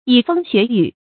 發音讀音
成語拼音 yǐ fēng xué yǔ